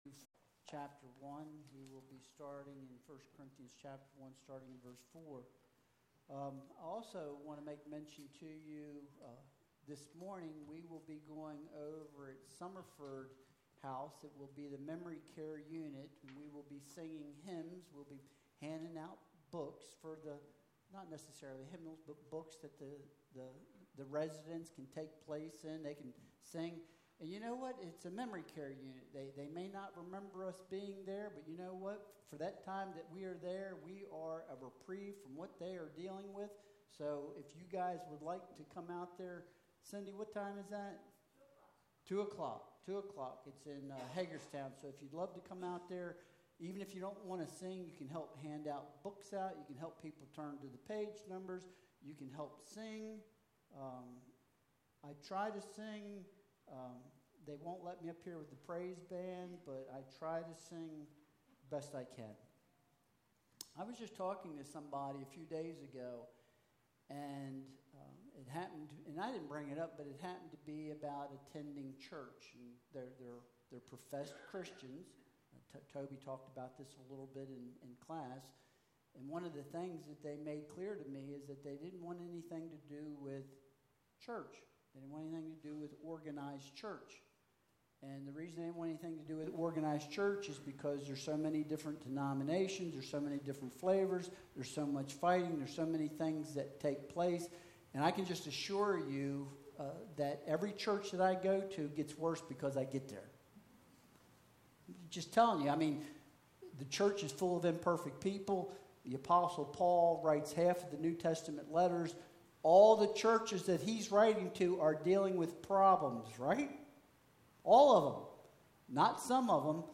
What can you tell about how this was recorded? Passage: 1 Corinthians 1.4-17 Service Type: Sunday Worship Service Download Files Bulletin « Joy and Jesus How Do You Respond to Evil?